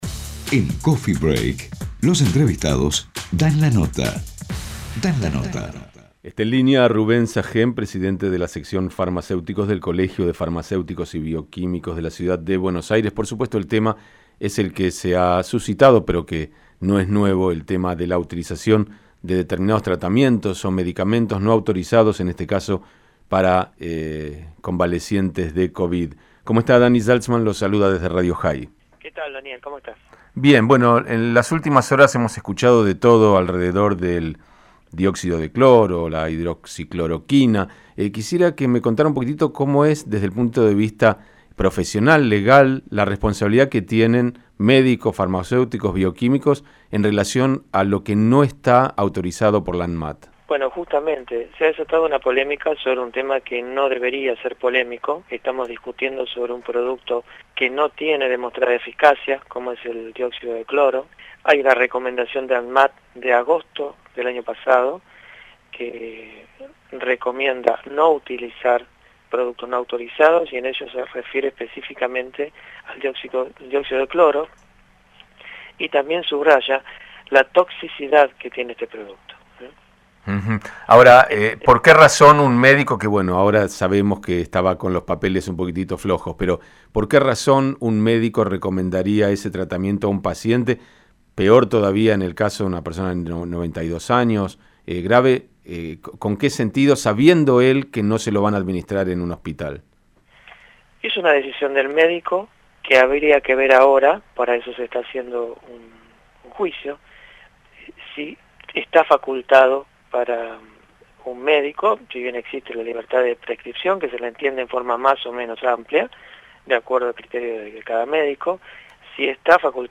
Sobre por qué un médico suministraría un producto no autorizado, el entrevistado manifestó que esa es una decisión del médico; que, en este caso, habría que ver lo que resuelve la Justicia; es decir, si ese médico está facultado para prescribir una intervención médica no autorizada, porque -explicó- en la receta, el profesional escribe que se suministre “como tratamiento compasivo” y no indica dosis, formulación del producto, concentración, ni forma de administración.